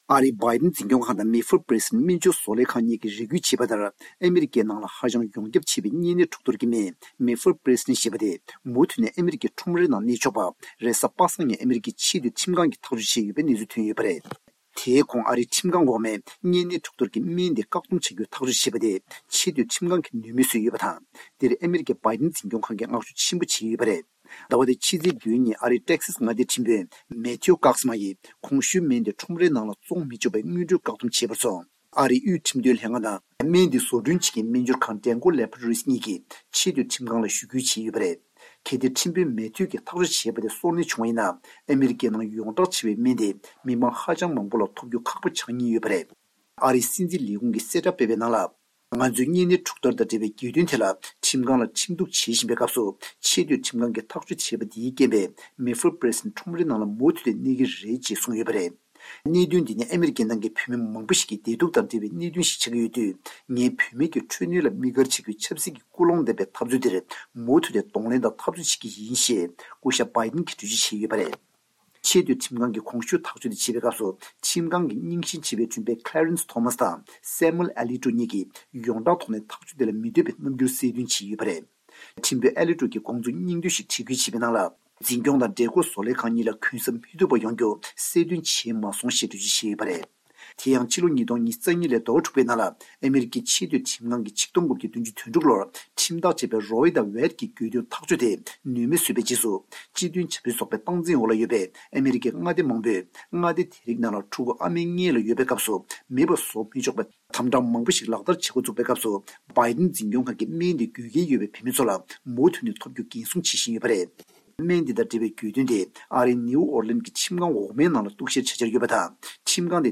གནས་ཚུལ་སྙན་སྒྲོན་ཞུ་ཡི་རེད།